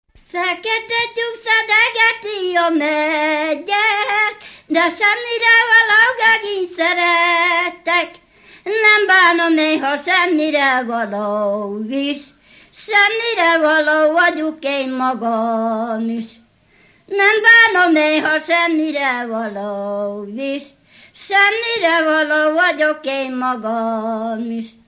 Alföld - Szabolcs vm. - Asszonyrészpuszta (Nyíracsád)
Műfaj: Lassú csárdás
Stílus: 1.1. Ereszkedő kvintváltó pentaton dallamok
Kadencia: 7 (5) b3 1